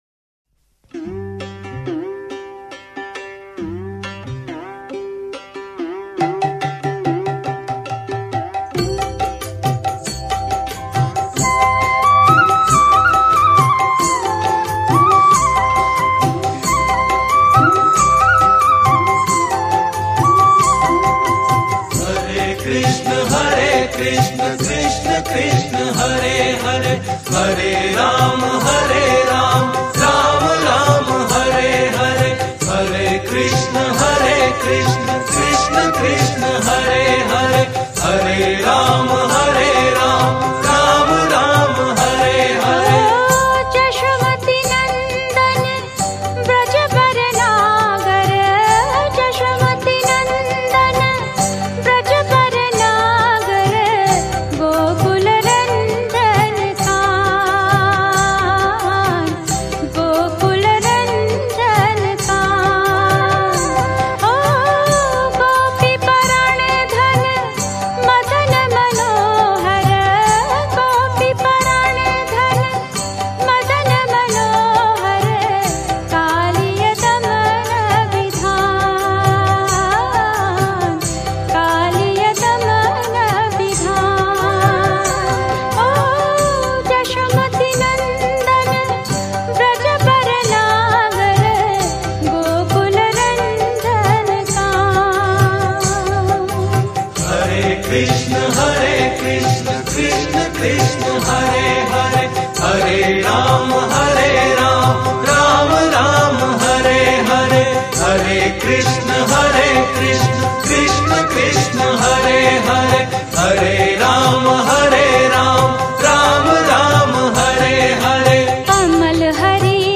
Shree Krishna Bhajans